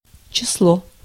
Ääntäminen
IPA: [dat]